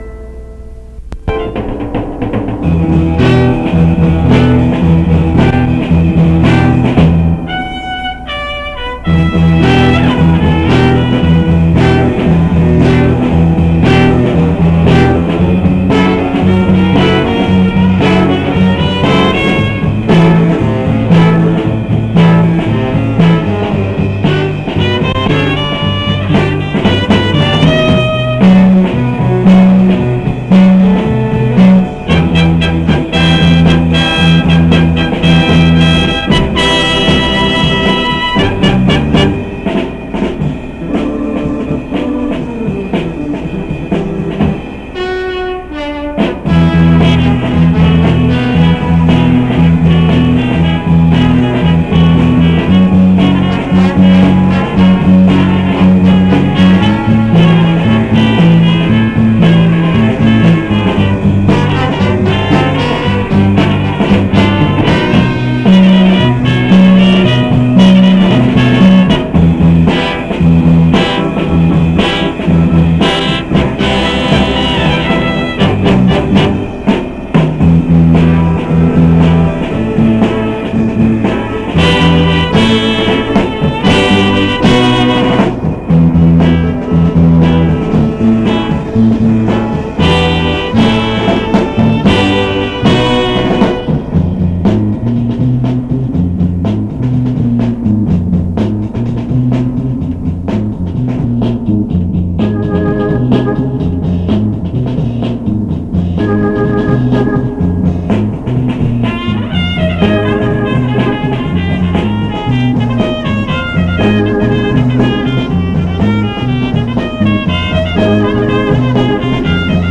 washington high school: 1969 summer jazz band